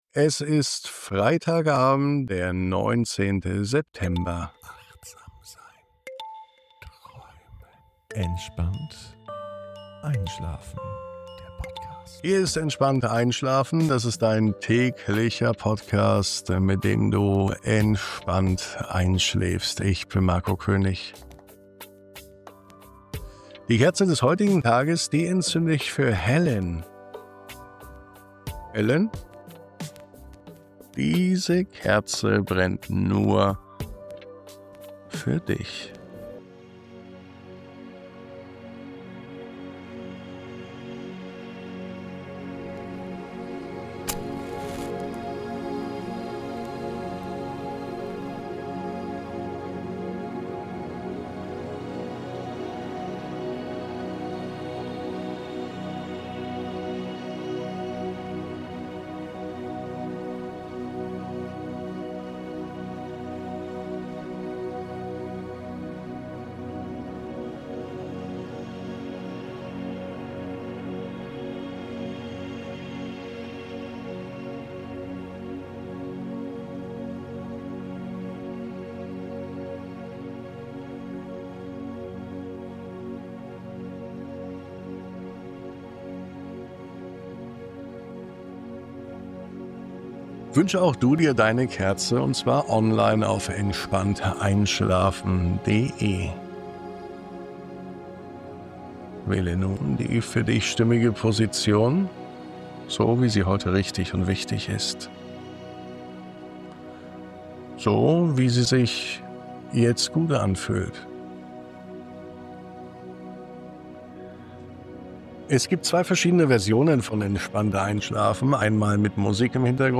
Diese Traumreise schenkt dir einen Abend voller kleiner, leiser Glücksmomente – zart wie der letzte Sonnenstrahl, wohlig wie ein vertrauter Duft, beruhigend wie ein Lächeln zur richtigen Zeit. Lass dich von diesen Lichtpunkten durch die Dunkelheit begleiten – hin zu deinem ganz persönlichen Frieden für die Nacht.